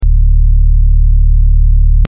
Low Hum
Low_hum.mp3